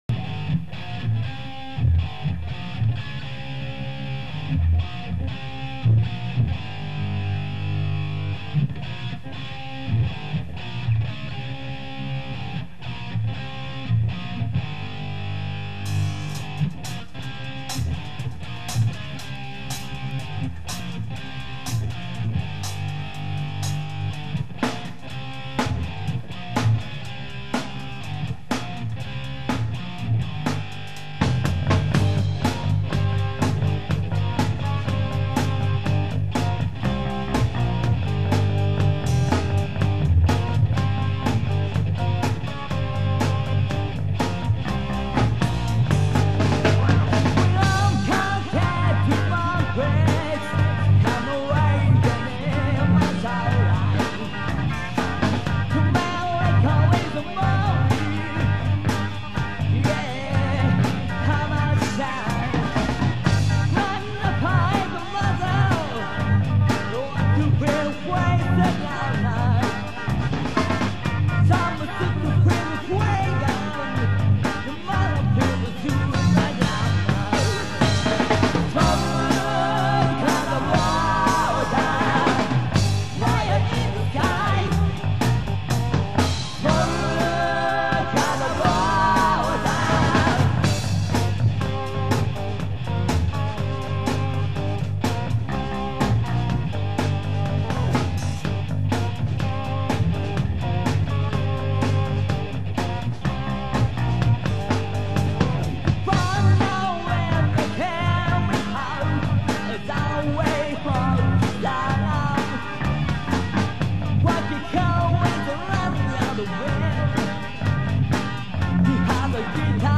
ベースと、私の歌が最悪です。
（ヘタクソ露呈のオンパレードですな）（ソロは自分の勝手なアレンジです）
タスカムの24chミキサーを使用したアナログ一発録りレコーディングですが
ベース弾いた奴は突き指でまともに弾けない上リズム悪いです。
せして、私の歌も声が抜けず、サビのハモリは、個人的に頂けないんです。
トラックダウンも散々リバーブかけ誤魔化そうとしていましたが酷い出来だったんで、
ノイズは酷いです。
後、ドラマーのスネアの音も良いかなぁ～？とか。
MIDの出方が、いかにもレスポールって音ですね。